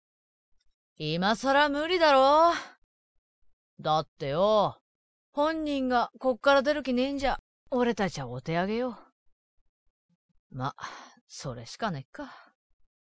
● サンプルボイス ●
↓お試し同然の無理無理とか、音質も、ノイズ処理などが適当なのも混じってます。
男03【青年・高〜中】 【１】
【１】真面目そう